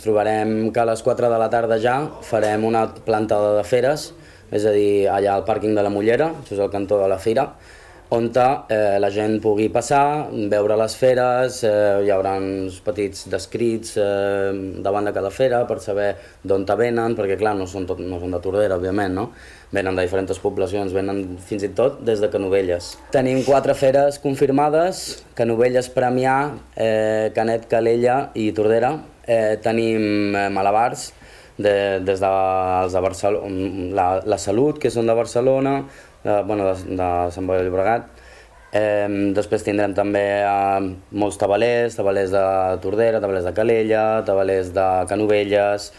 A la tarda destaca la plantada de besties de foc, una cercavila de malabars i tabalers i un espectacle finals al parc de la Mullera. Ho explica la colla de diables de Tordera.